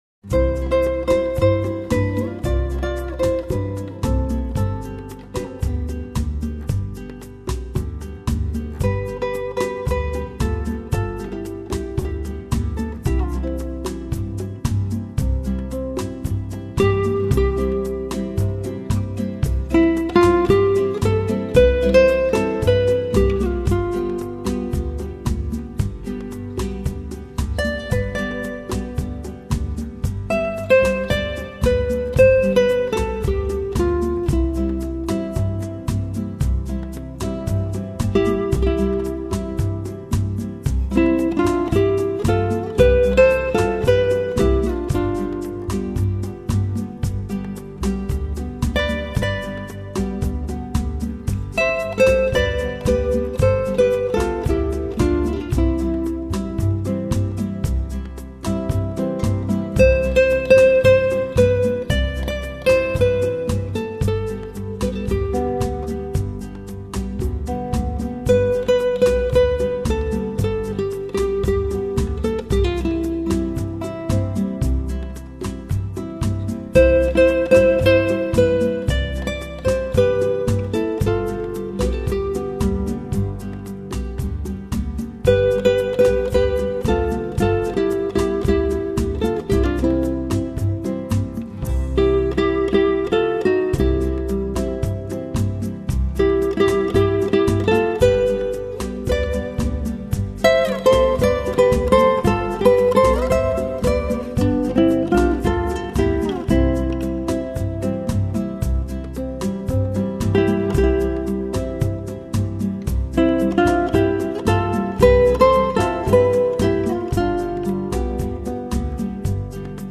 乐器以吉他为主，配合轻轻的 敲击和贝斯，加上浪漫的“伦巴”节奏